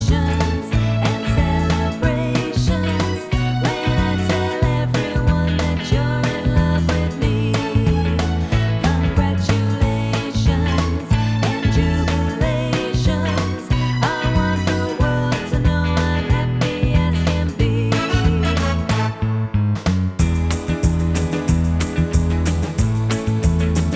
Slow Songs Pop (1960s) 3:54 Buy £1.50